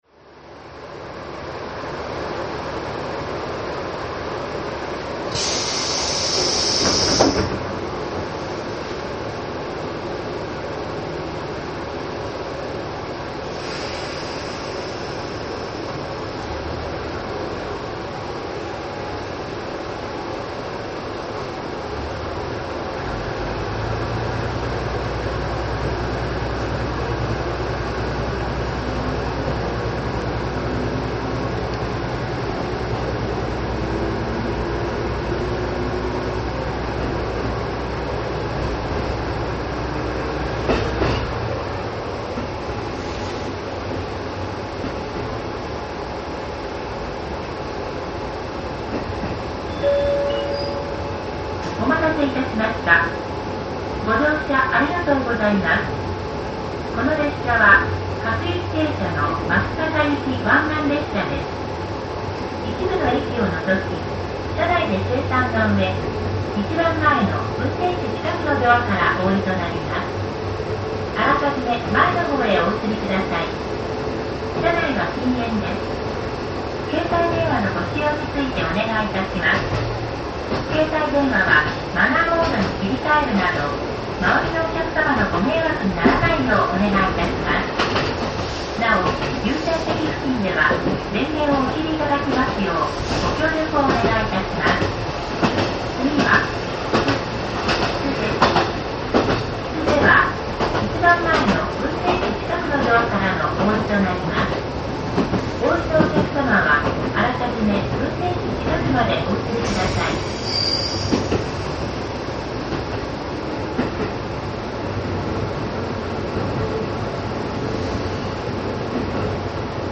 平成16年12月29日伊勢奥津を出る名松線408Cキハ11-4　　　　　　　　　　　　　　　　　　　　　MP-3　1680KB　3分43秒
最初少し加速するだけで下り坂を転がっていくキハ11。嬉しいことに昔の丙規格の短いレールが残っていました。